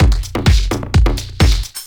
Electrohouse Loop 128 BPM (25).wav